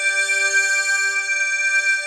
orb_glow_03.wav